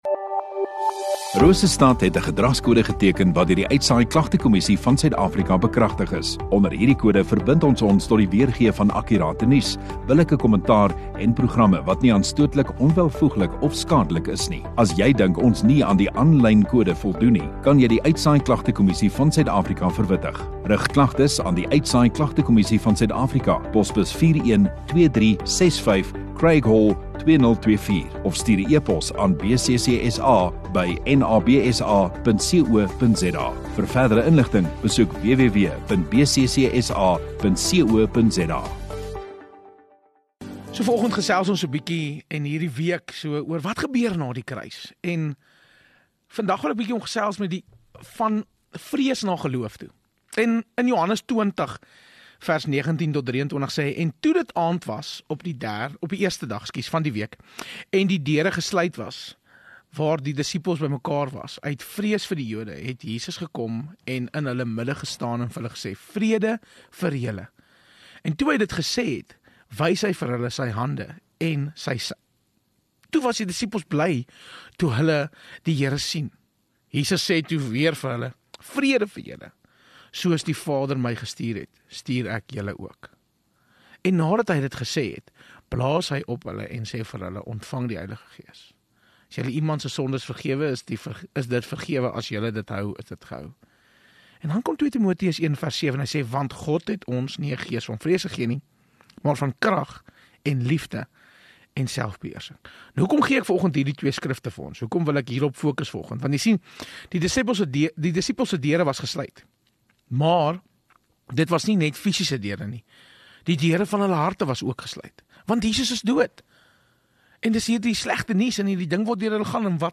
14 Apr Dinsdag Oggenddiens